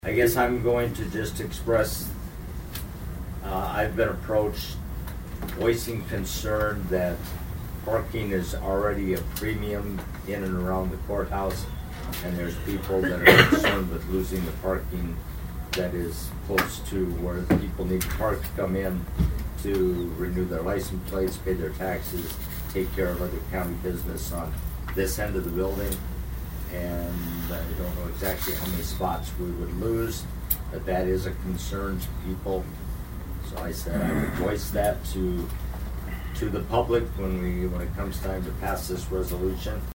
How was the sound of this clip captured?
ABERDEEN, S.D.(HubCityRadio)- At Tuesday’s Brown County Commission meeting, the commissioners address a resolution dealing with the possible expansion of Dacotah Prairie Museum.